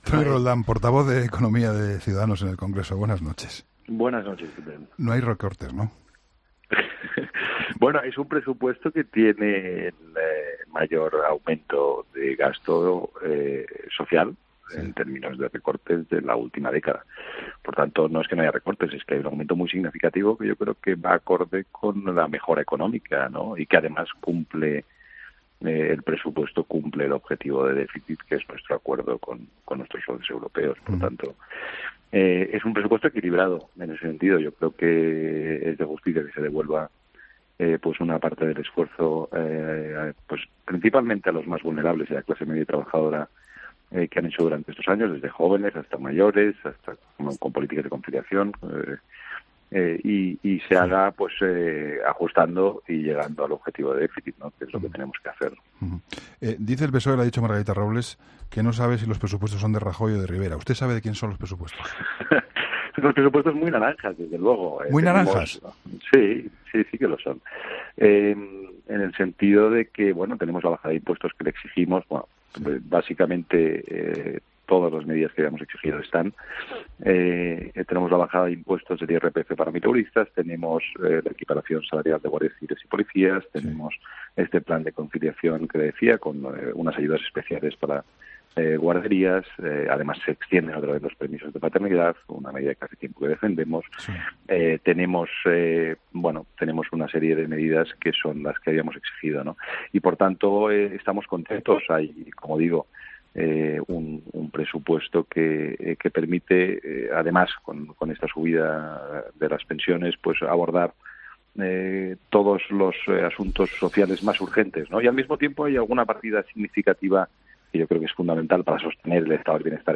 El portavoz de economía de Ciudadanos, Toni Roldán ha señalado en 'La Linterna' con Juan Pablo Colmenarejo que los Presupuestos Generales del Estado...